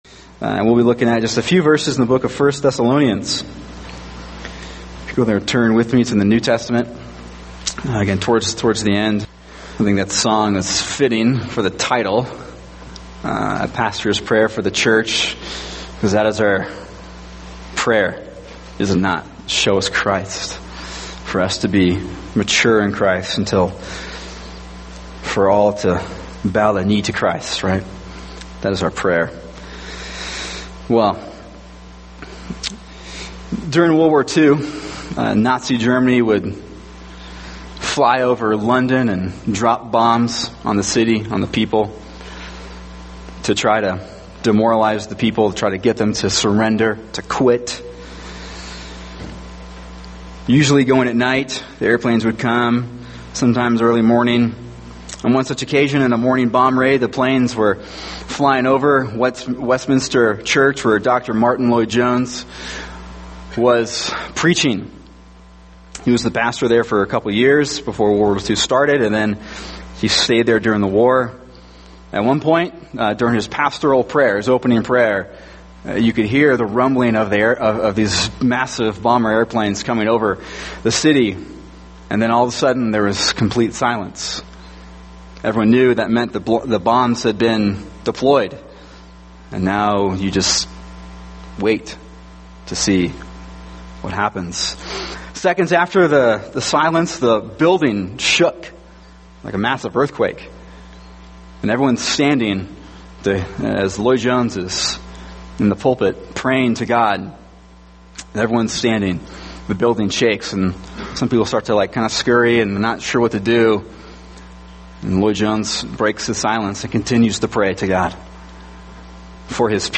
[sermon] 1 Thessalonians 3:11-13 – A Pastors Prayer for the Church | Cornerstone Church - Jackson Hole